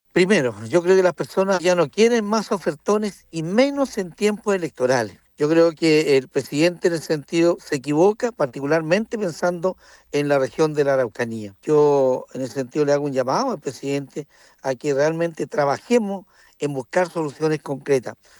El parlamentario de Renovación Nacional, Juan Carlos Beltrán, los calificó como “ofertones” en un año electoral.